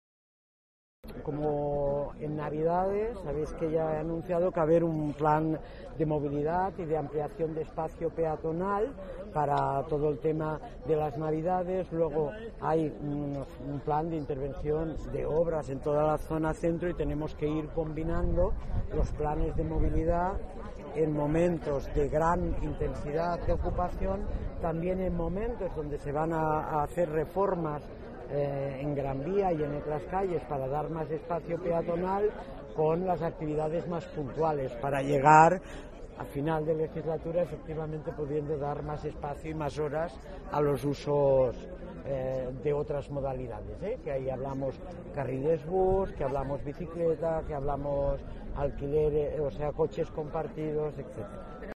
Nueva ventana:Inés Sabanés habla de los cambios para mejorar la movilidad en la ciudad